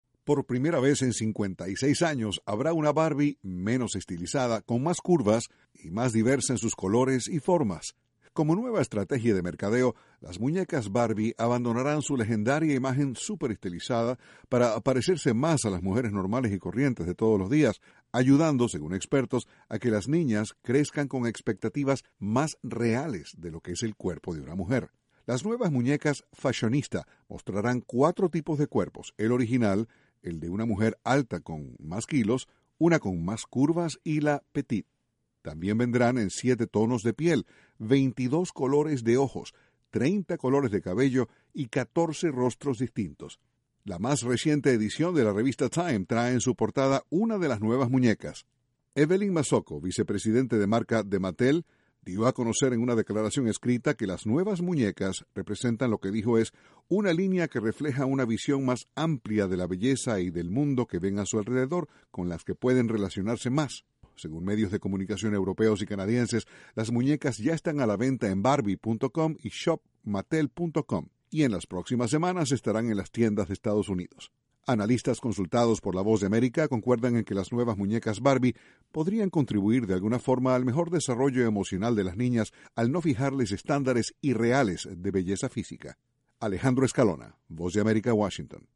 Una nueva muñeca Barbie irrumpe en el mercado mundial, mostrando un nuevo cuerpo. Desde la Voz de América, Washington, informa